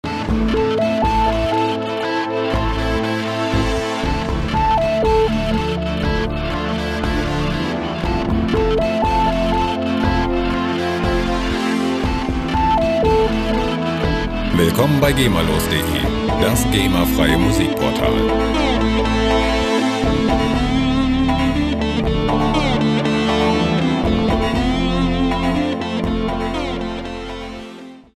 Musikstil: Rock
Tempo: 120 bpm